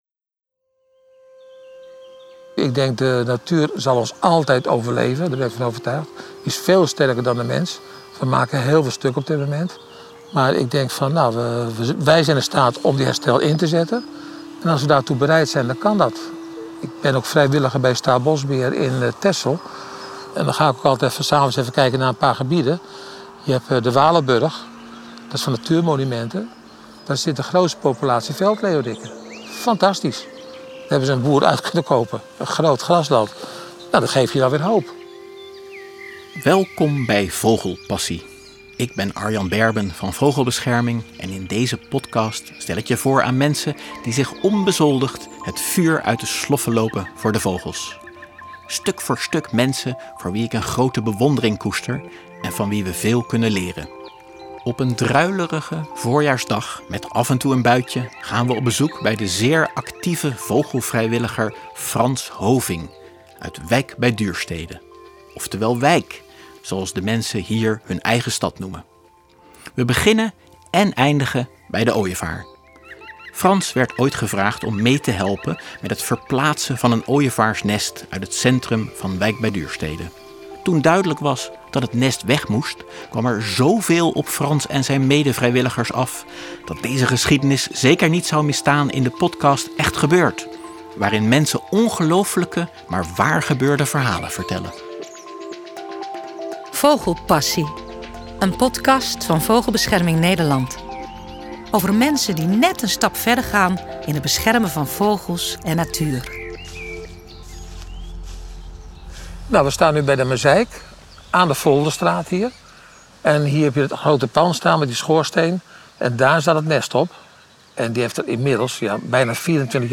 De reportage voor Vogelpassie in Wijk bij Duurstede eindigde toch weer bij de ooievaars, maar op een ander plekje, net buiten de stad